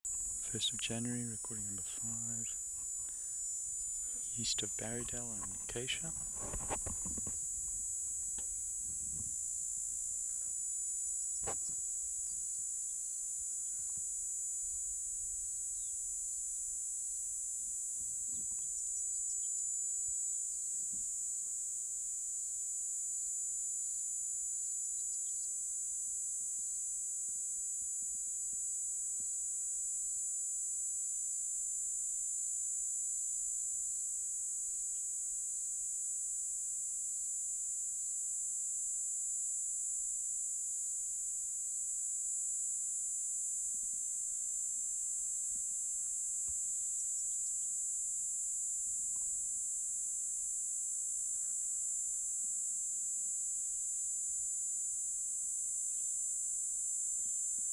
MHV 902 P.sp12 nr Barrydale.wav